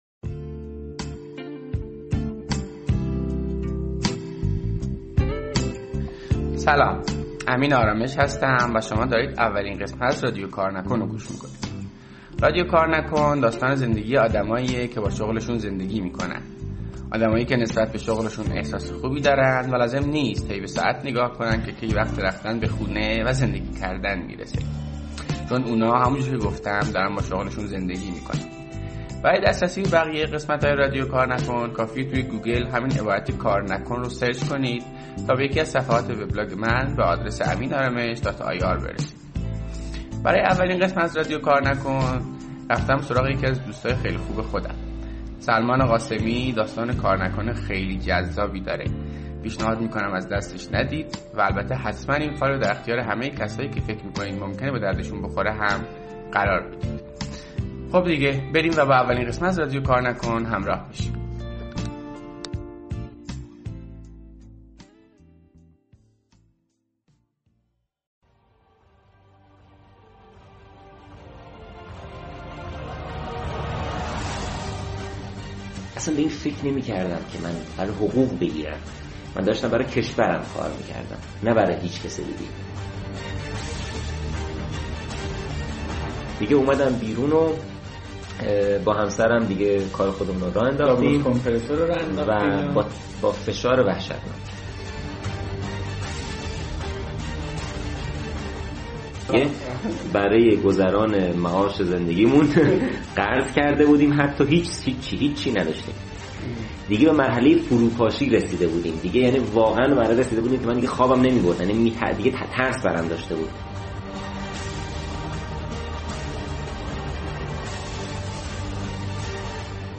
گفتگو کردم.